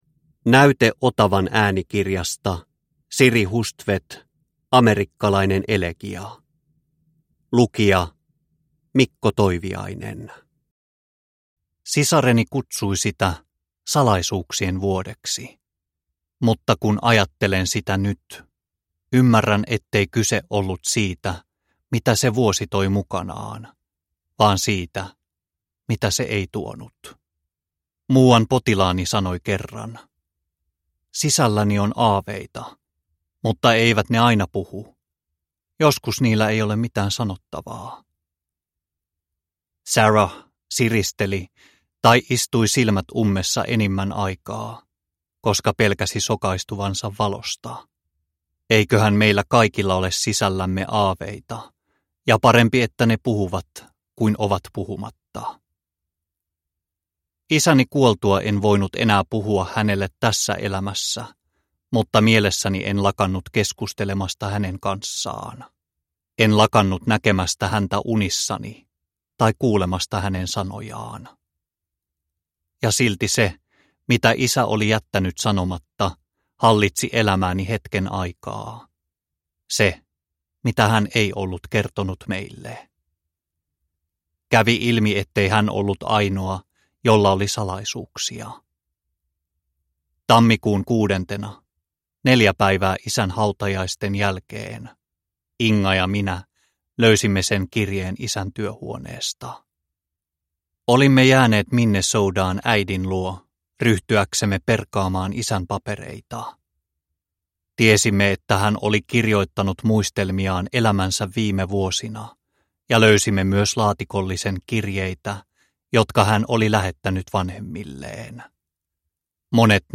Amerikkalainen elegia – Ljudbok – Laddas ner